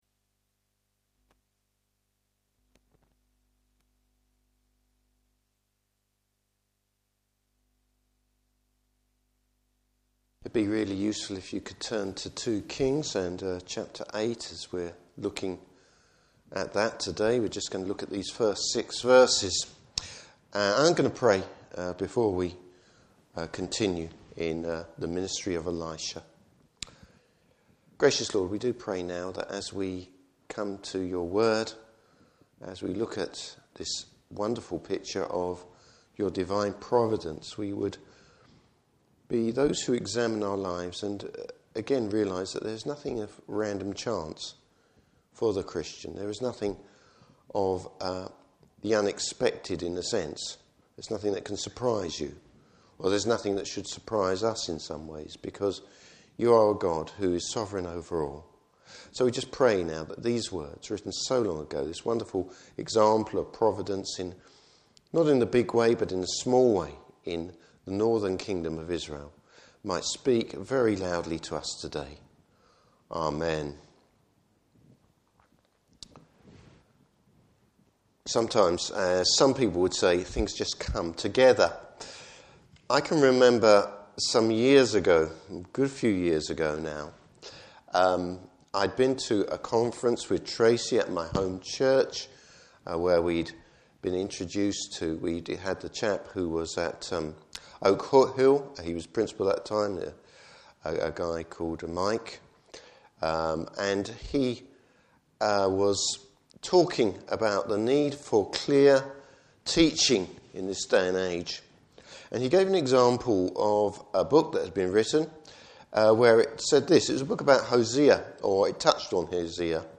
Service Type: Evening Service God’s providence and care for the faithful.